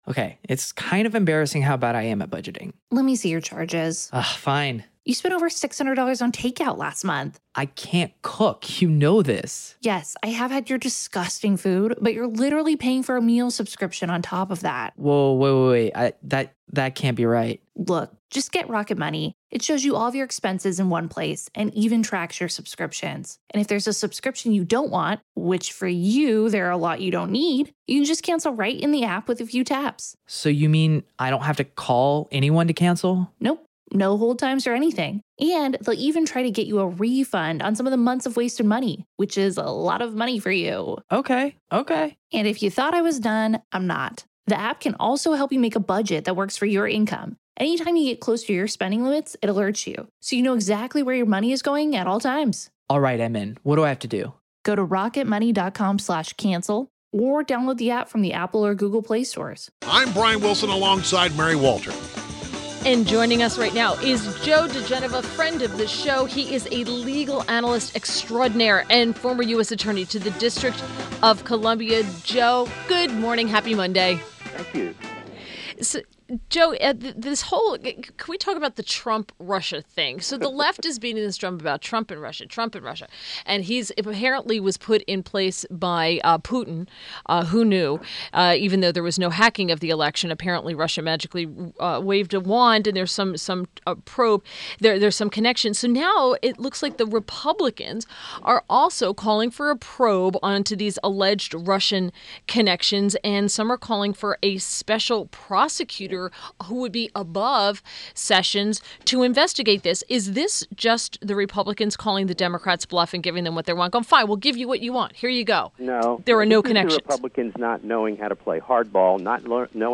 WMAL Interview - JOE DIGENOVA - 02.27.17
INTERVIEW — JOE DIGENOVA – legal analyst and former U.S. Attorney to the District of Columbia